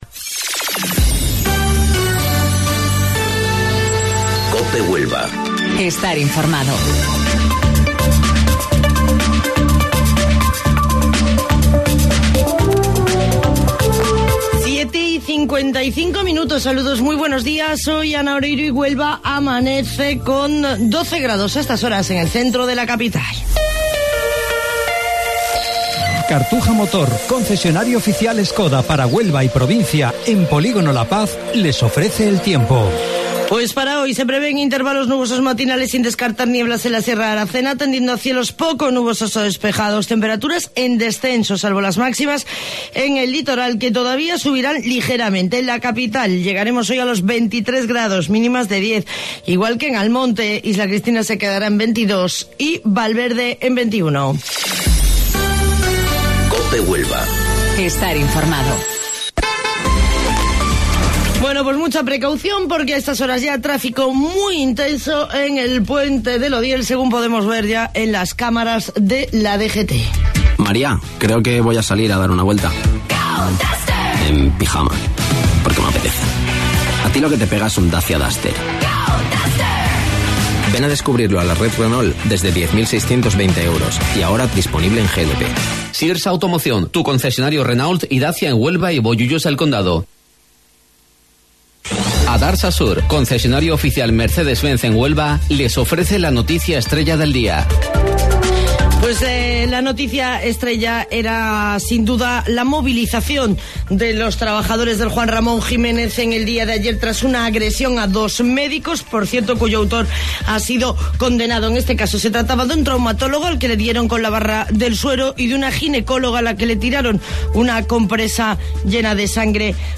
AUDIO: Informativo Local 07:55 del 13 de Marzo